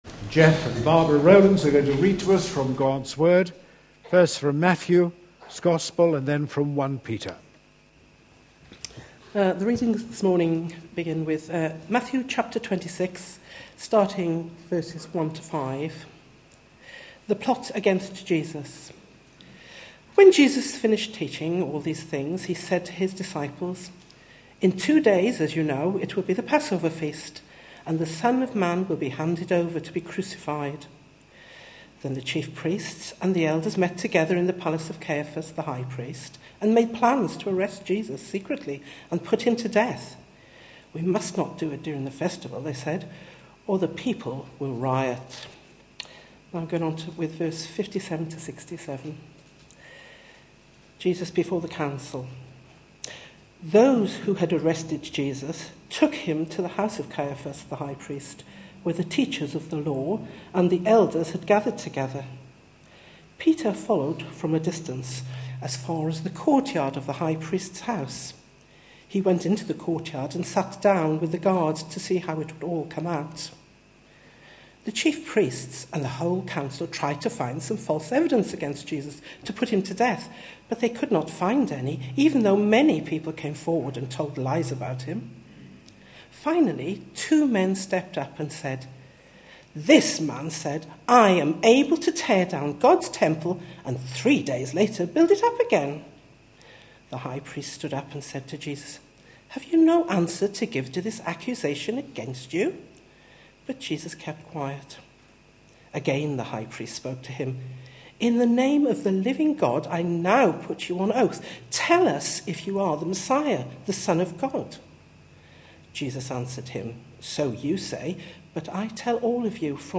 A sermon preached on 3rd March, 2013, as part of our Passion Profiles and Places -- Lent 2013. series.